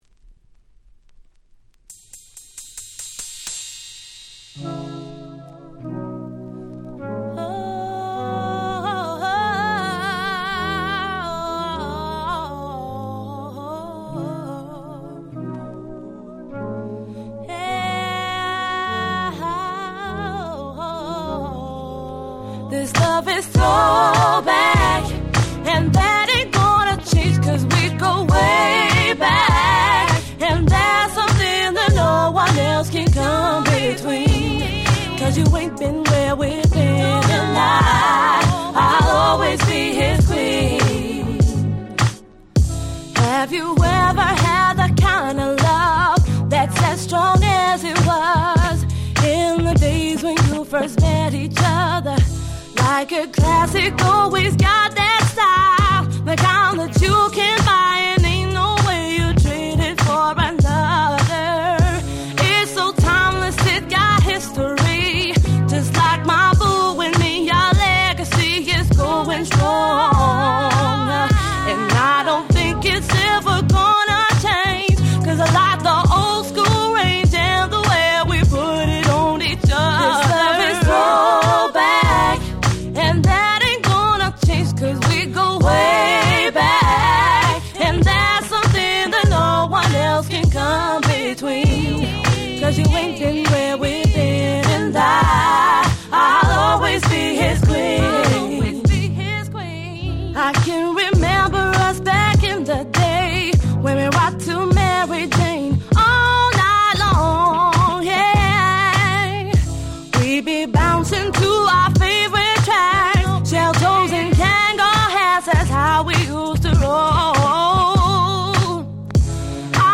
06' Very Nice R&B EP !!